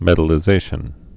(mĕdl-ĭ-zāshən)